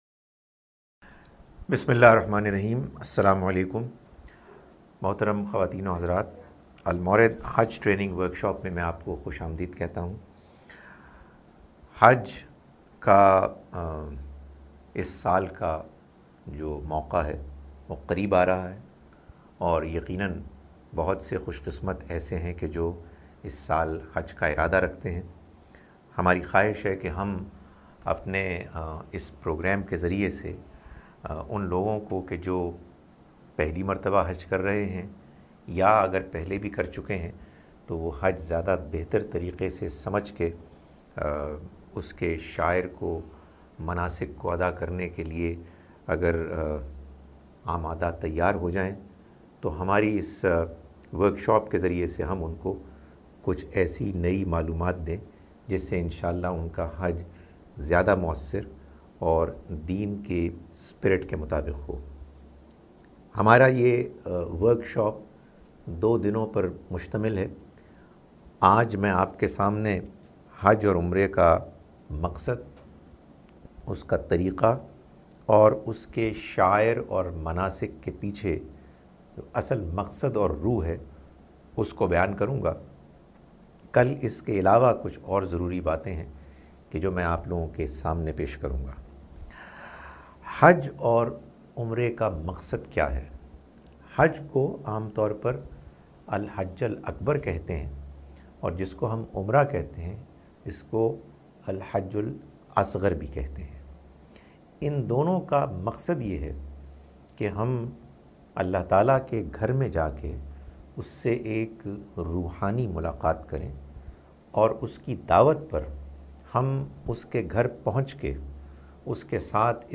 Participants also asked their questions live during the workshop.